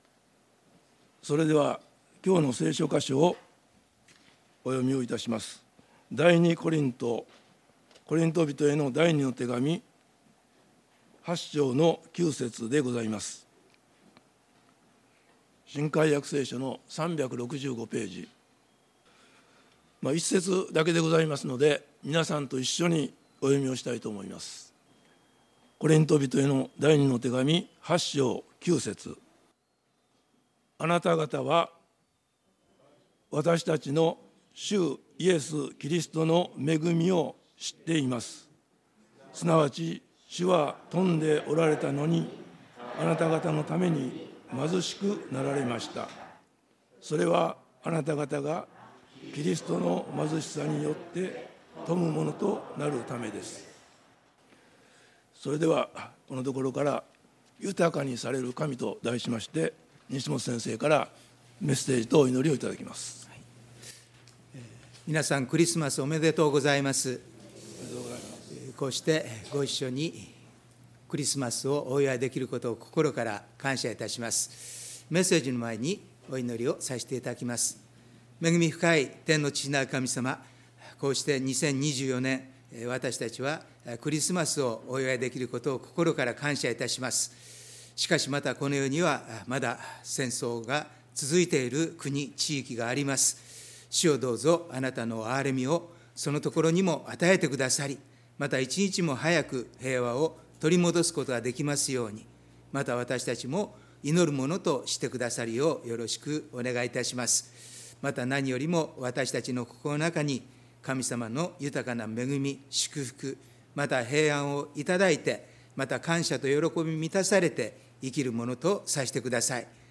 礼拝メッセージ「豊かにされる神」│日本イエス・キリスト教団 柏 原 教 会